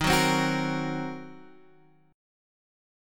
D#m6 chord